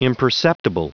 Prononciation du mot imperceptible en anglais (fichier audio)
imperceptible.wav